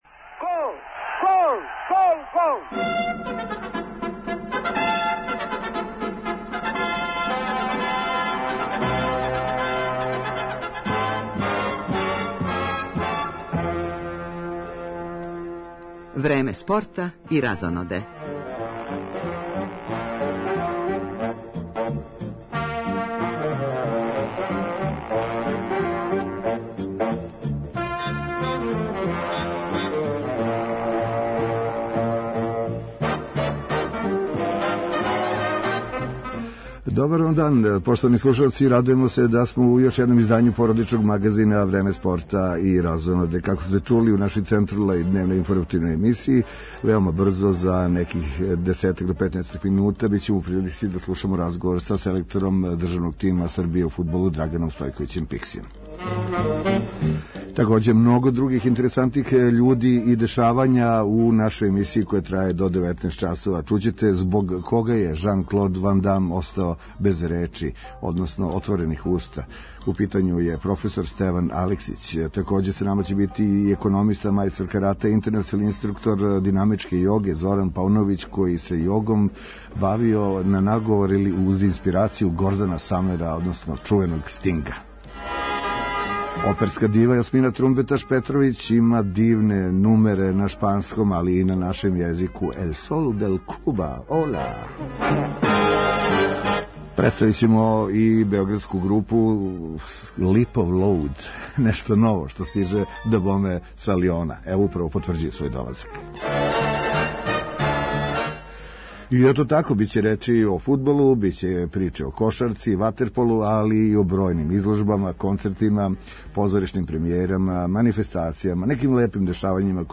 И ове суботе испред микрофона породичног магазина Радио Београда 1 продефиловаће низ људи интересантних због својих делатности, из разних области света који нас окружује.